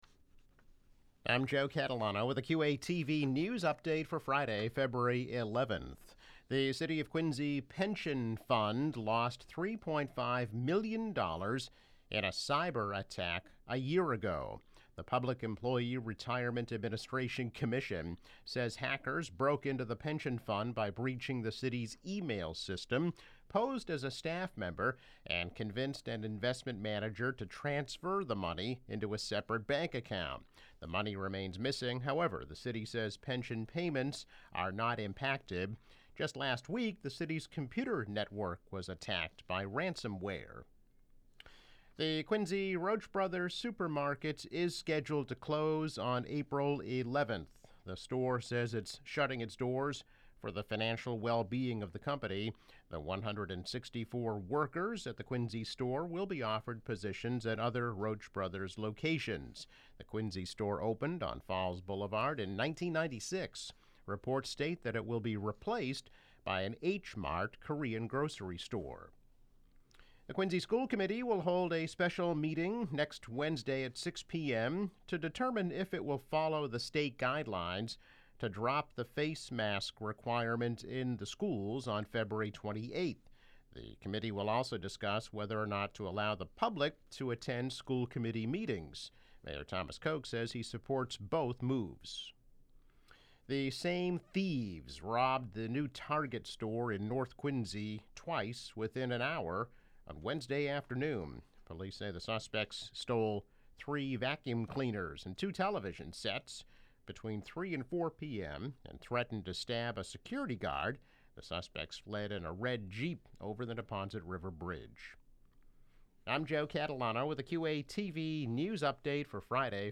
News Update - February 11, 2022